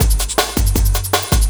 06 LOOP09 -R.wav